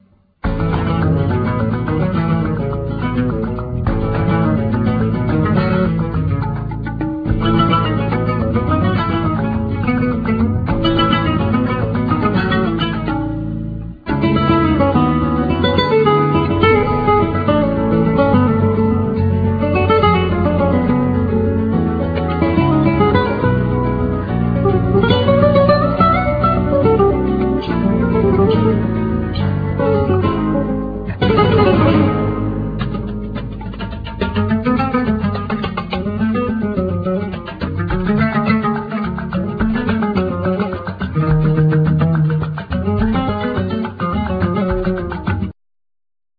Guitar,Lute
Vocals,Hand Clapping
Percussion
Harmonica
E-Bass
Cajon,Hand Clapping,Jaleos